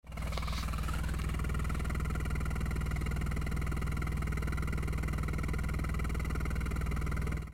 GTbox06&Sビビり対策済み専用サイレンサー（アイドリング）
ビビり音がほとんど聞こえないのが分かりますし
より洗練された綺麗な音質になっています。
copen_la400-kakimoto_genuine_baffle_mute_idling.mp3